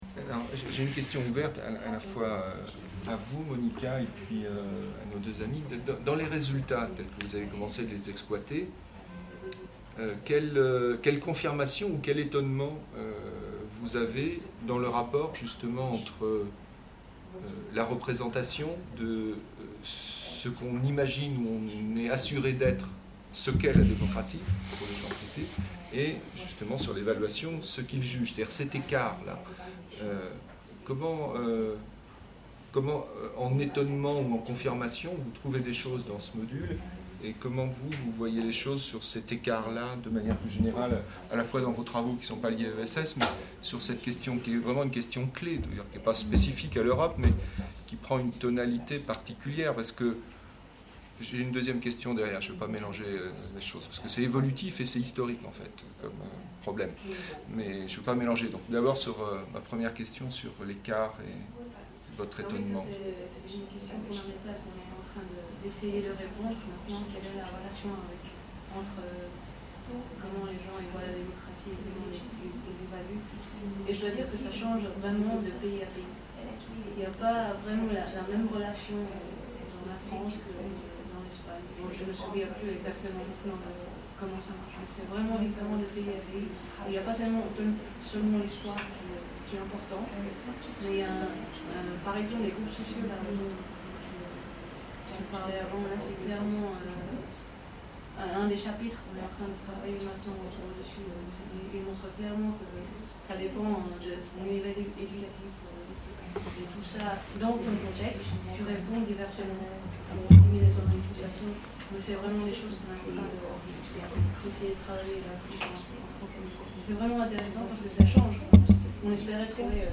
ESS Topline results presentation - Europeans and democracy - Question (2/4) : représentation et évaluation de la démocratie et rapport au système | Canal U